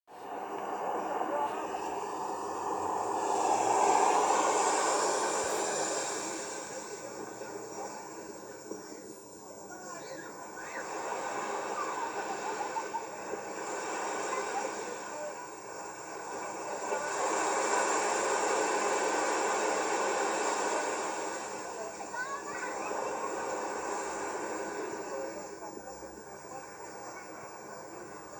Playa Flamingo CARTAGO